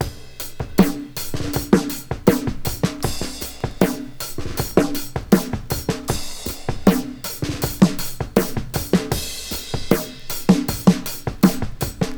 • 79 Bpm Fresh Breakbeat Sample G# Key.wav
Free drum beat - kick tuned to the G# note. Loudest frequency: 1912Hz
79-bpm-fresh-breakbeat-sample-g-sharp-key-lQg.wav